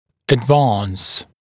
[əd’vɑːns][эд’ва:нс]продвигать(ся) вперёд; движение вперёд, продвижение, прогресс, достижение